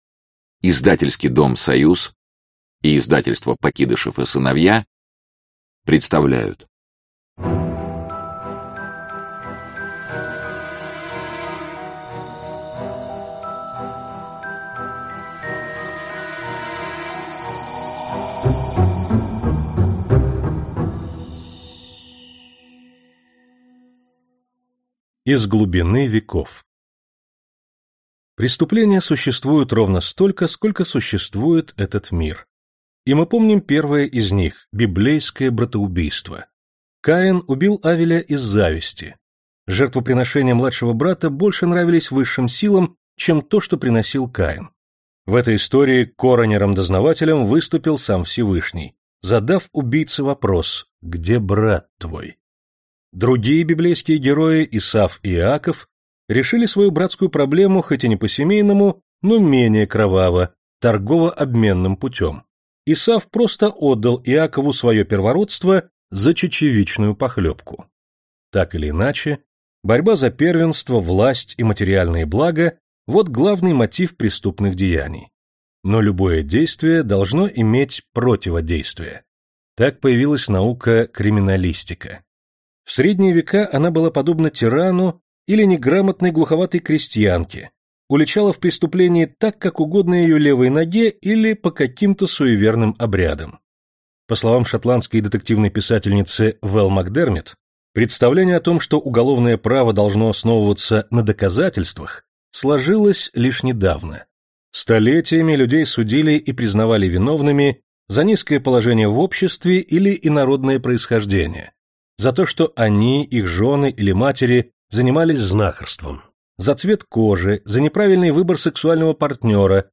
Аудиокнига Криминалистика. Игры разума | Библиотека аудиокниг
Игры разума Автор Сборник Читает аудиокнигу Всеволод Кузнецов.